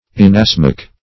Inasmuch \In`as*much"\, adv. [In + as + much.]